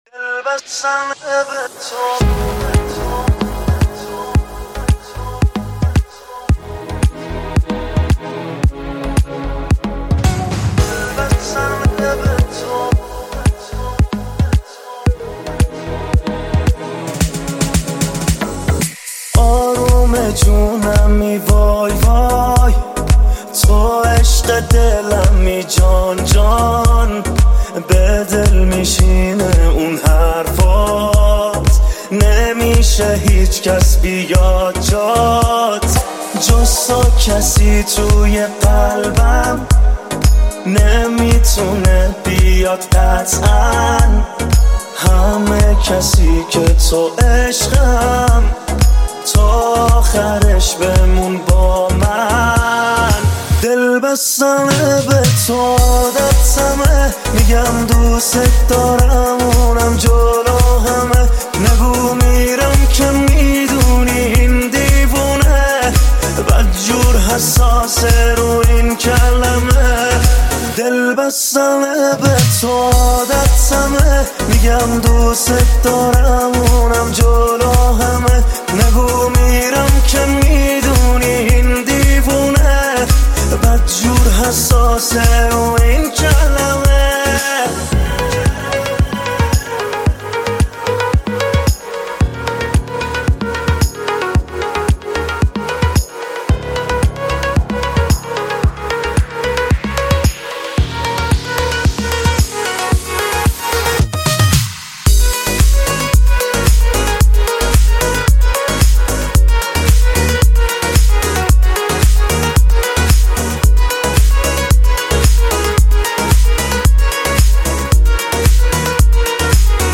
اهنگ بسیار زیبای اروم